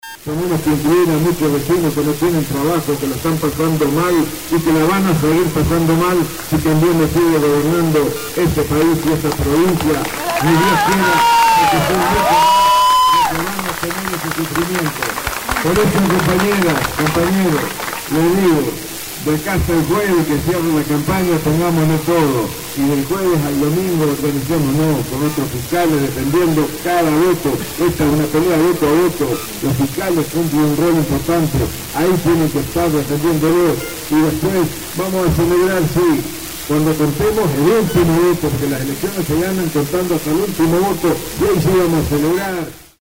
Desde Alcaraz, el gobernador expresó: «Esta es una pelea voto a voto, las elecciones se ganan contando hasta el último voto”, en lo que fue un enérgico mensaje a la militancia y a los fiscales, principalmente a estos últimos por la gran responsabilidad que tienen.
A la hora de la palabras, Bordet, luego de agradecer las presencias y de destacar la figura de Julio Solanas como primer candidato en su lista de diputados, brindó un discurso enérgico dirigido a la militancia justicialista de cara a las elecciones del próximo domingo, en el que quedó demostrada su gran preocupación.
Bordet-en-Alcaraz.mp3